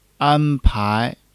an1-pai2.mp3